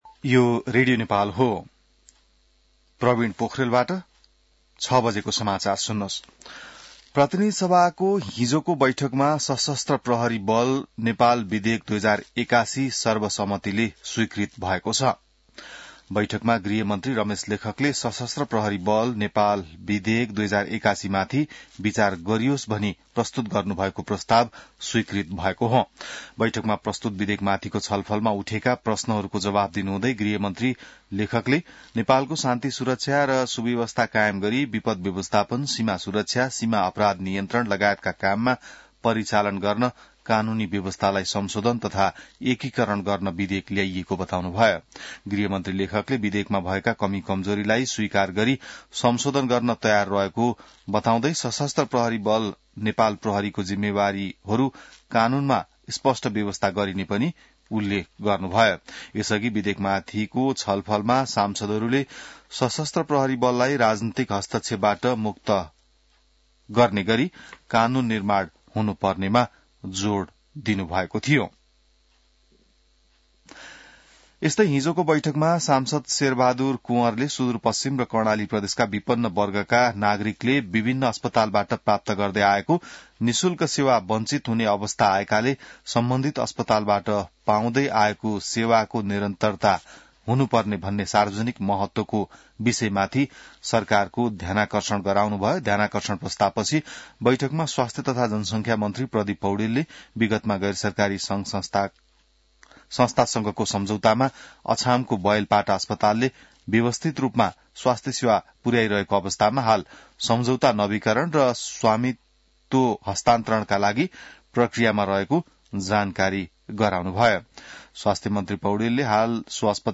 बिहान ६ बजेको नेपाली समाचार : १४ फागुन , २०८१